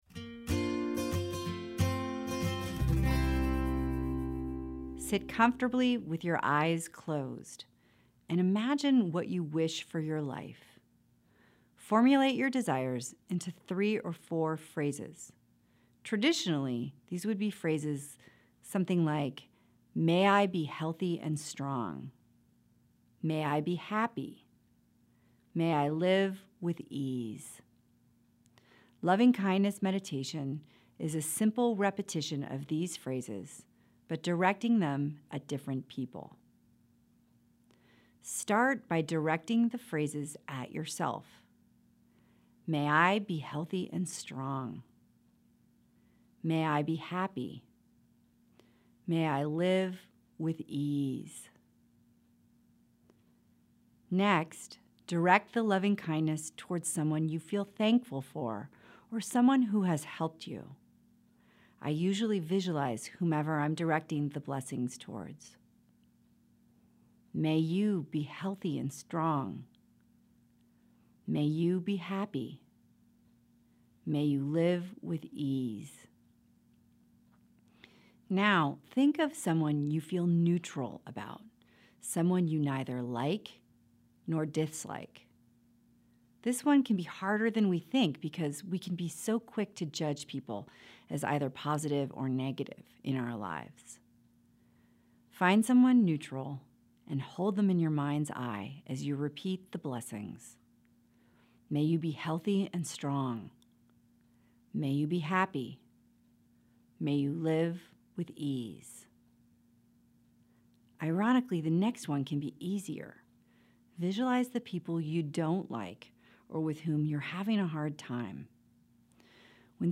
LOVING-KINDNESS MEDITATION AUDIO (MP3)
Follow along and practice with me.